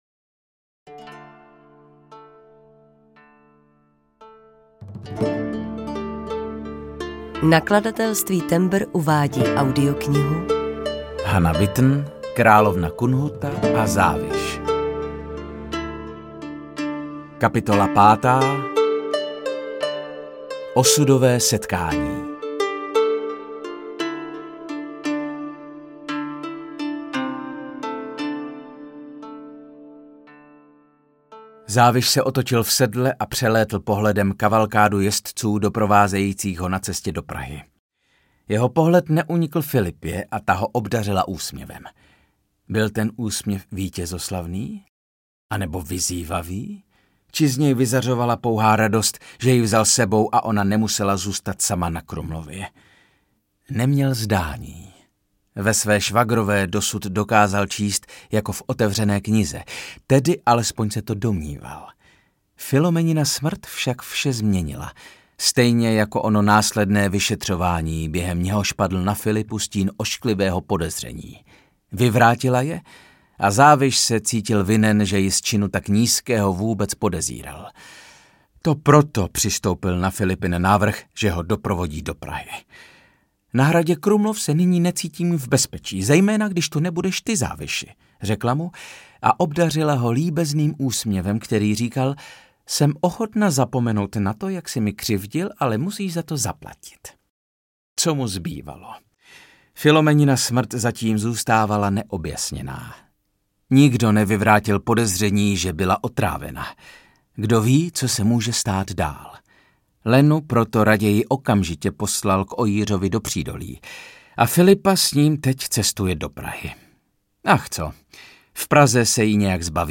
Královna Kunhuta a Záviš audiokniha
Ukázka z knihy